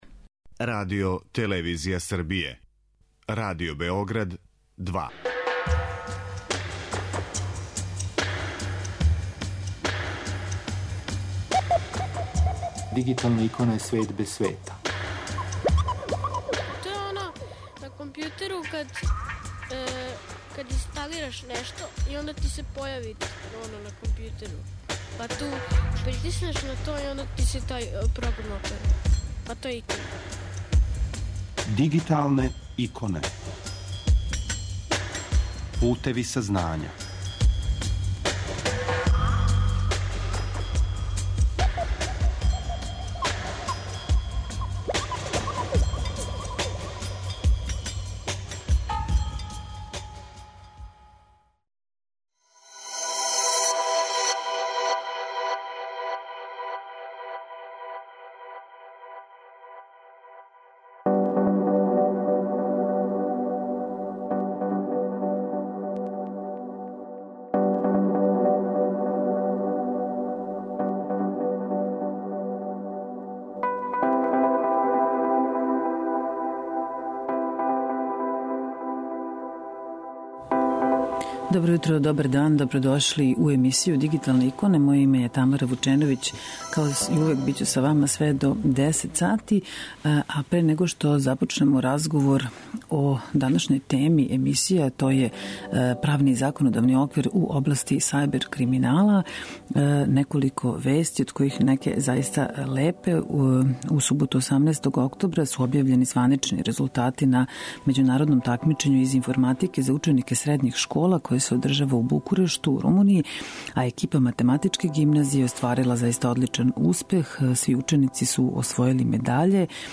Oво су нека од питања на која ћемо одговоре потражити у разговору са посебним тужиоцем за високотехнолошки криминал и чланом Комисије за спровођење Националне стратегије реформе правосуђа за период 2013-2018. године, господином Бранком Стаменковићем.